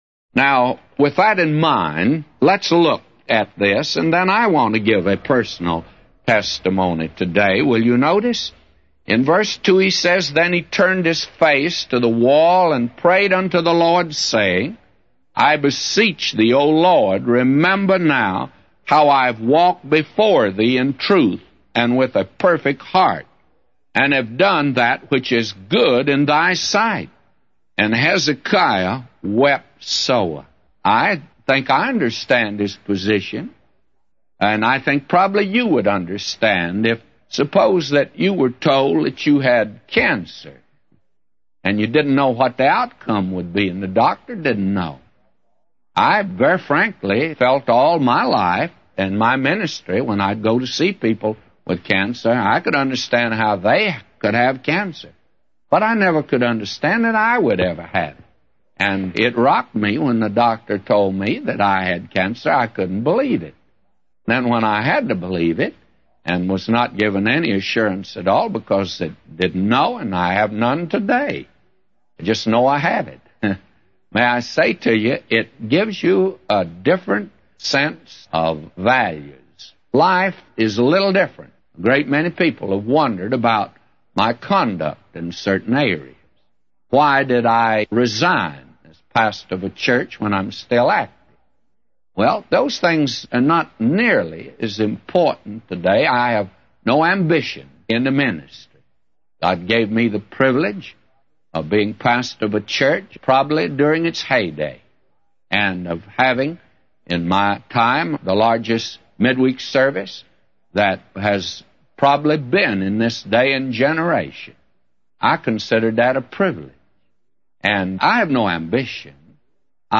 A Commentary By J Vernon MCgee For 2 Kings 20:2-999